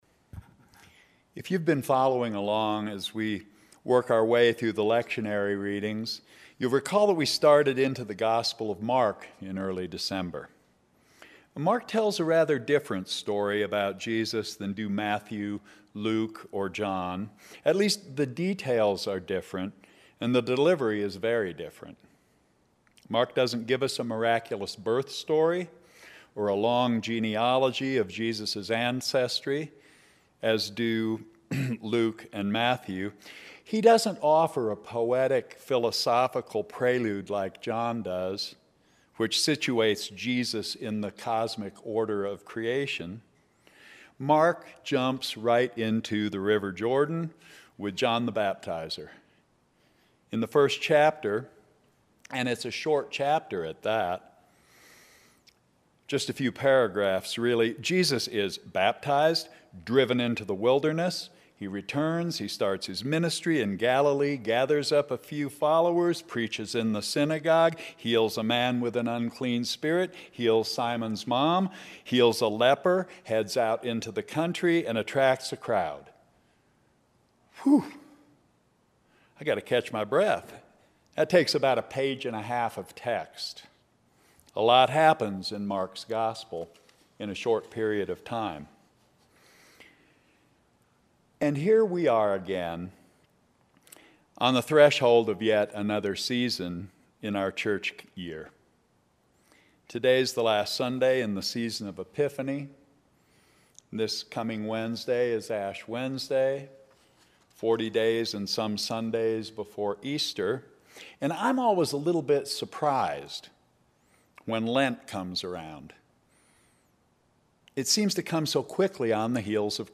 Sermons
St. John's Episcopal Church Last Sunday After the Epiphany